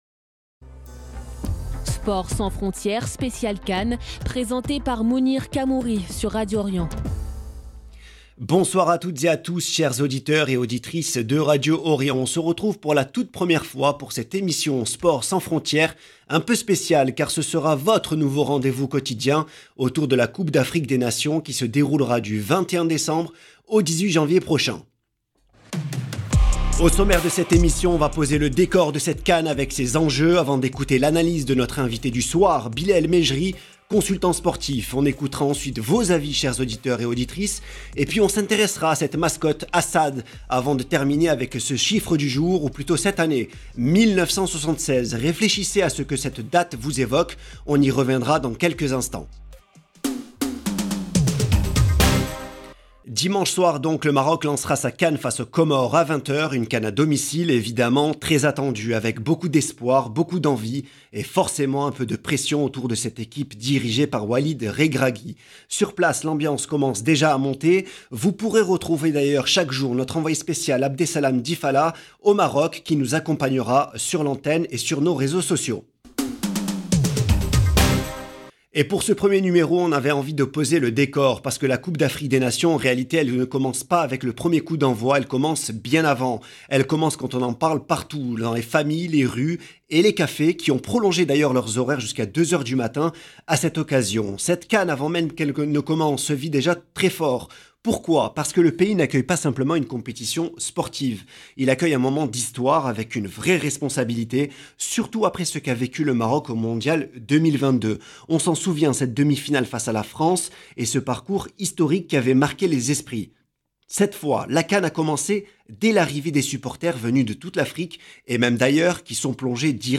consultant sportif